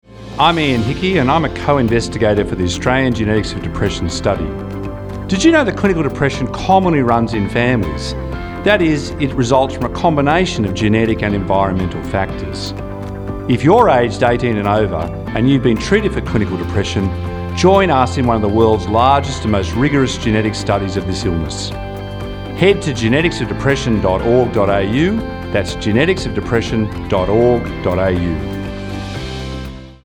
Australian Genetics of Depression Study – Radio CSA
Listen to the Australian Genetics of Depression Study Radio Community Service Announcement here: